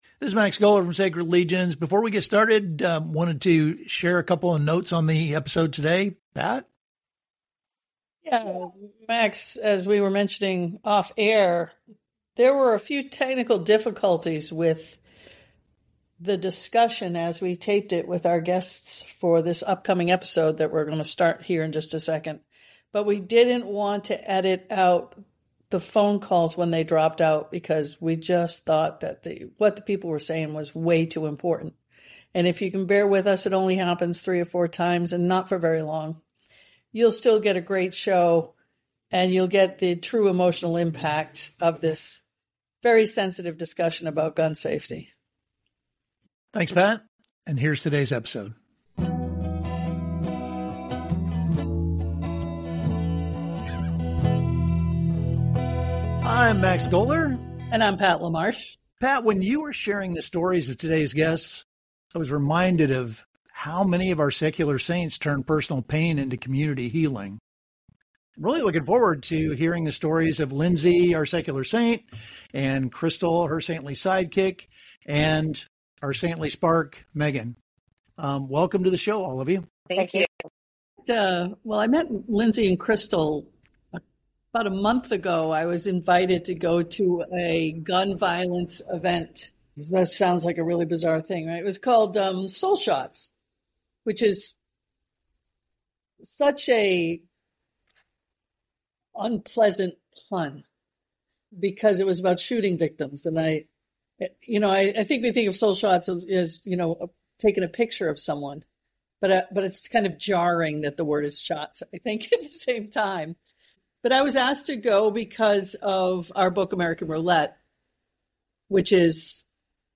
They discuss the journey of transforming personal pain into community healing, the importance of support systems, and the need for advocacy against gun violence. The conversation highlights the ongoing struggle with grief, the creation of meaningful events to honor lost loved ones, and the desire to build a movement that supports others facing similar challenges.